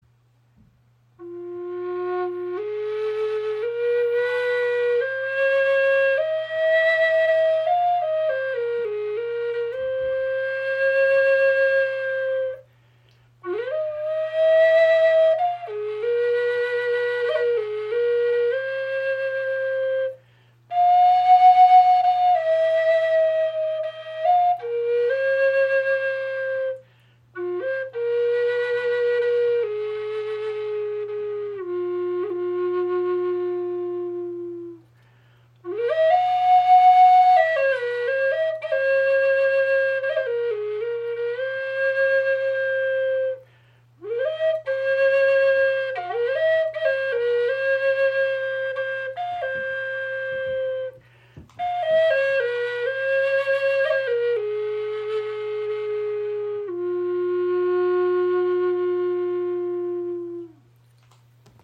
Die Spirit Flute EarthTone in F♯-Moll (432 Hz) aus Spanish Cedar überzeugt mit warmem, klarem Klang.
Alle Spirit Flöten dieser Serie sind auf 432 Hz gestimmt.